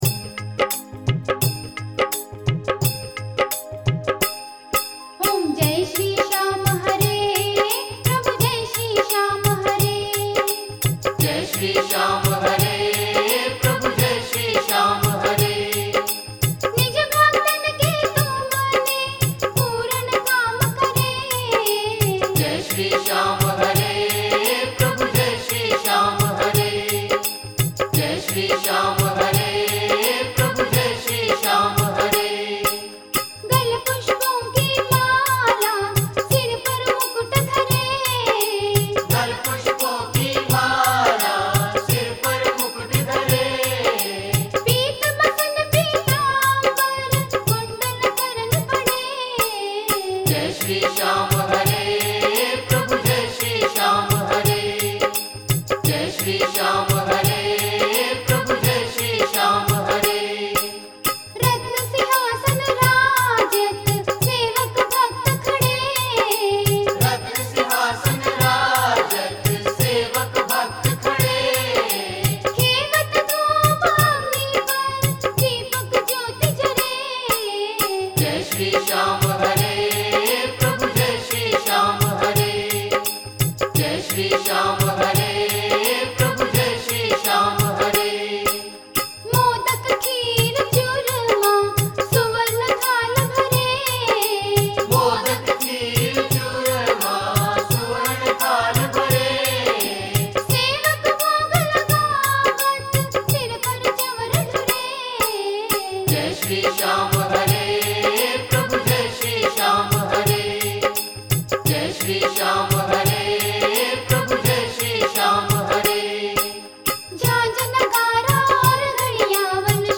Aartiyan